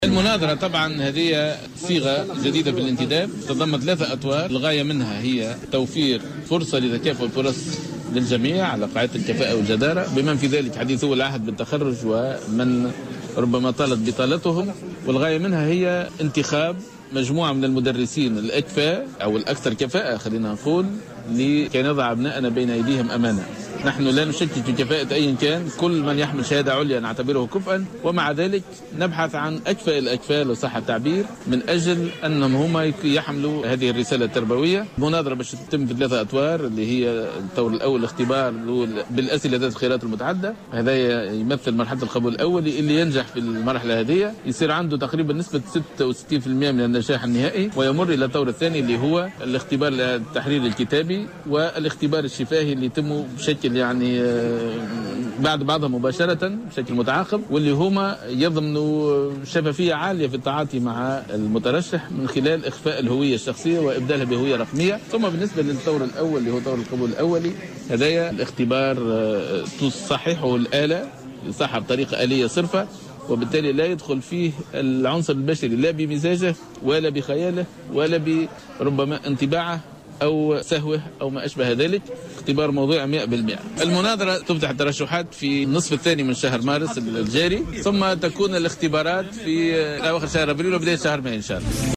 تحدث اليوم،الثلاثاء وزير التربية،فتحي الجراي في تصريح ل"جوهرة أف أم" على هامش انعقاد لجنة الشؤون التربية في المجلس الوطني التأسيسي عن صيغة جديدة لإجراء مناظرة "الكاباس" .